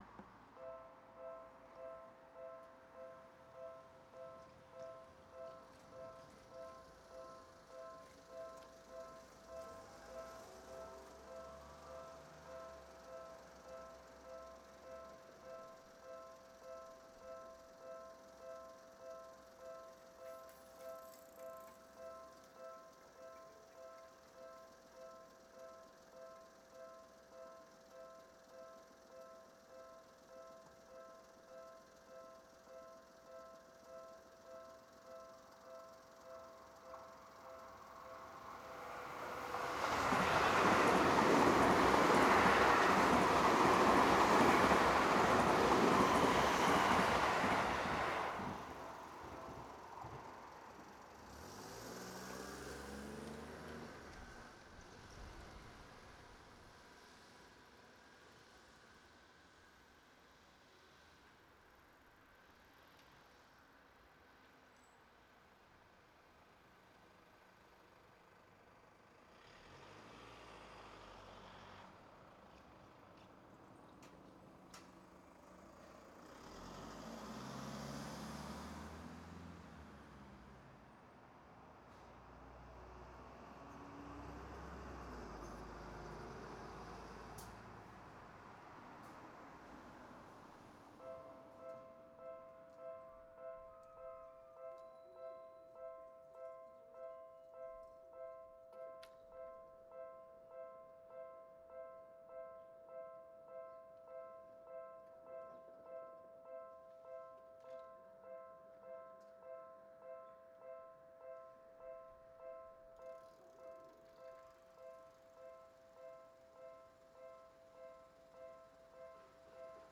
レコーダーはDR-100、内蔵マイク
風が少し強かったのですが、
途中でレールの継ぎ目にマイクを向けました。
そのあとにラビューが通過。
内蔵マイク 指向性ステレオマイク
LOW CUT 80Hz